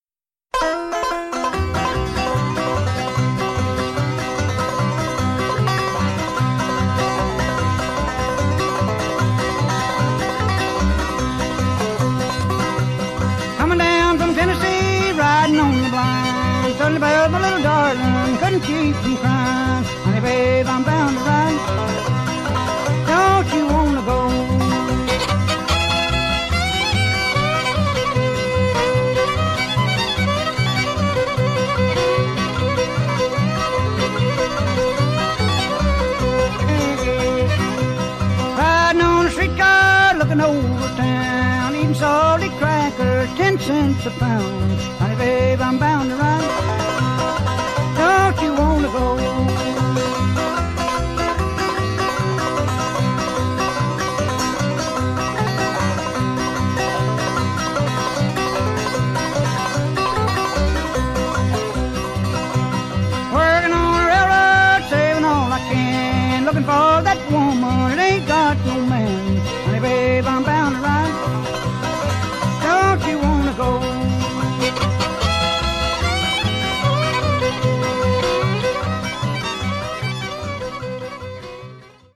Traditional
Listen to Ralph Stanley perform "Bound To Ride" frailing style (mp3)